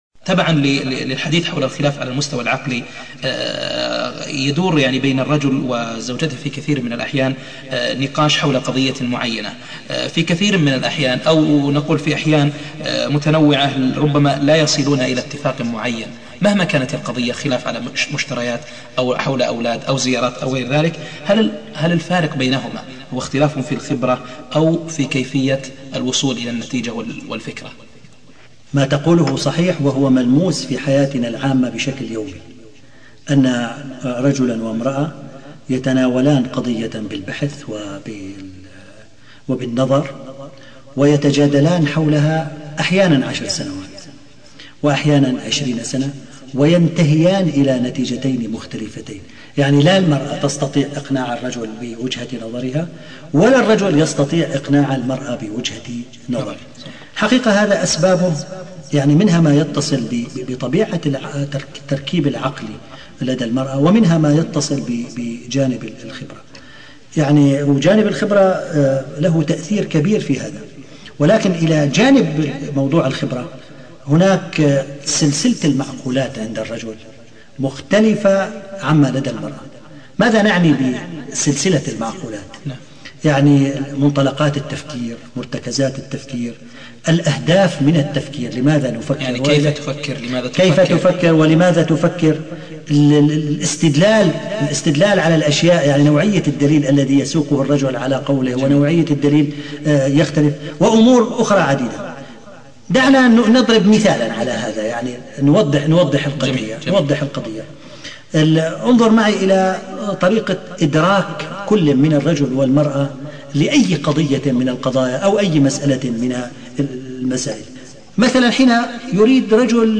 أرشيف الإسلام - ~ أرشيف صوتي لدروس وخطب ومحاضرات الدكتور عبد الكريم بكار